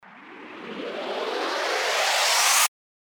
FX-1844-RISER
FX-1844-RISER.mp3